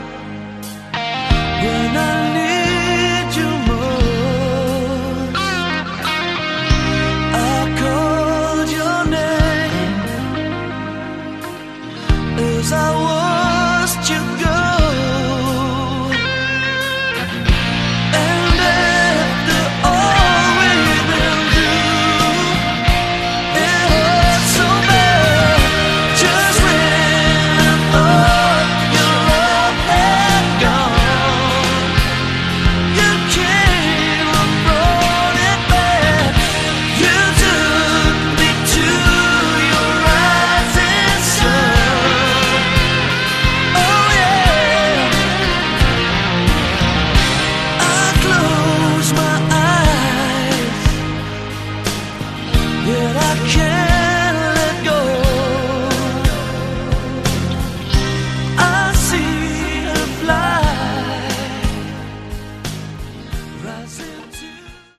Category: AOR
vocals, keyboards
guitars
drums
bass
Very keyboard drive rock.